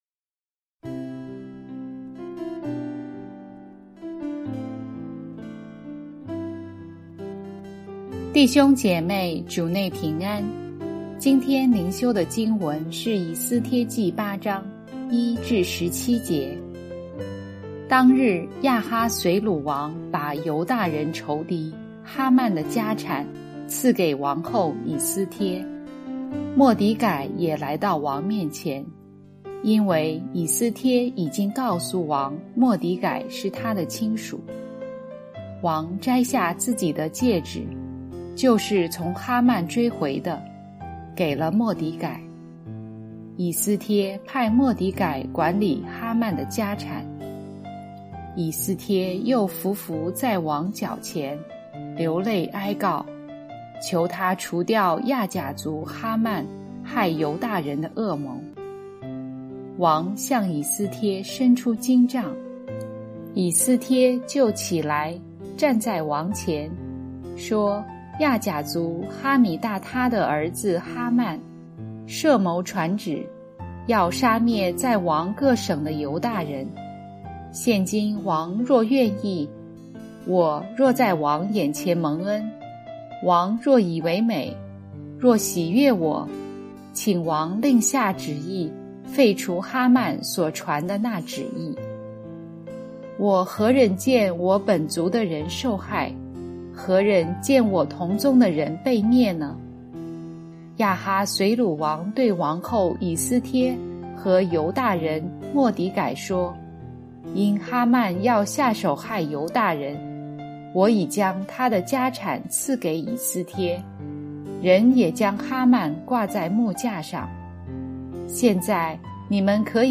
牧長同工分享：代求者